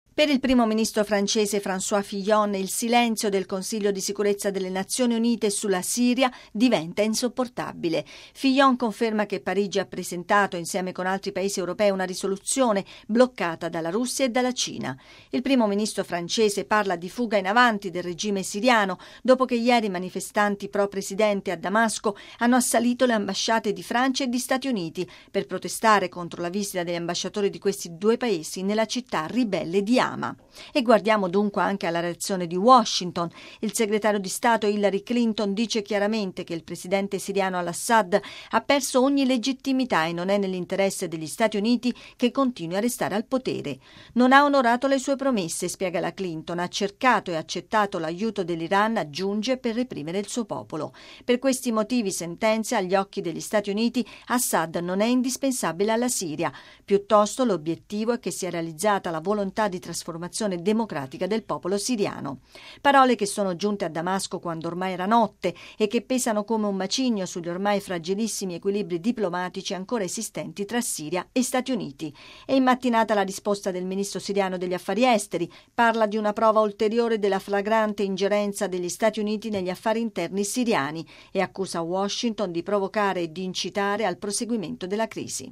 Gli Stati Uniti affermano che ha perso legittimità. Ieri le ambasciate di Parigi e Washington a Damasco sono state attaccate da sostenitori di Assad. Il servizio